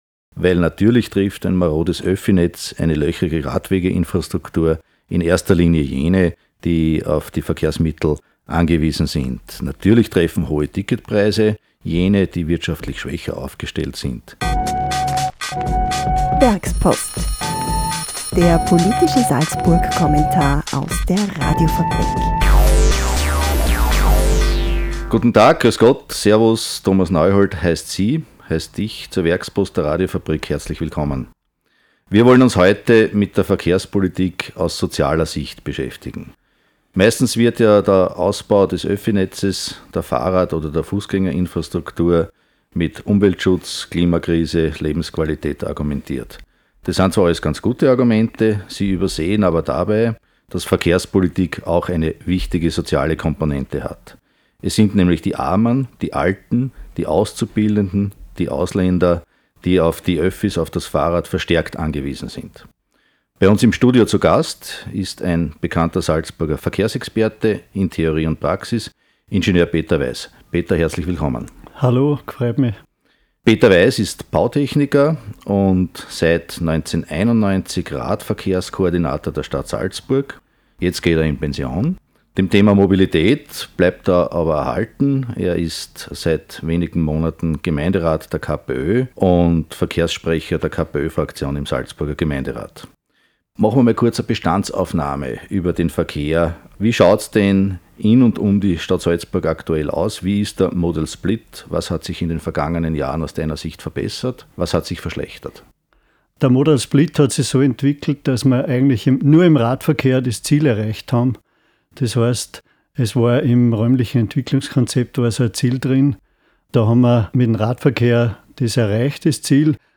Der Werkspodcast vertieft das jeweilige Thema des Werkspost-Kommentars in einem Studiogespräch.